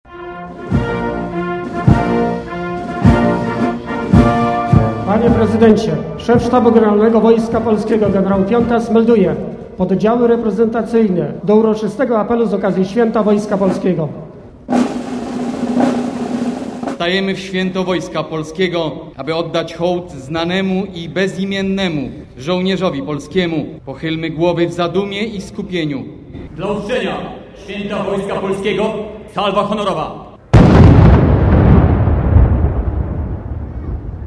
Salwa Honorowa
Po apelu oddano salwę honorową.
Po zaprzysiężeniu nastąpiła uroczysta zmiana wart przed Grobem Nieznanego Żołnierza na pl. Piłsudskiego w Warszawie.
salwa_honorowa.mp3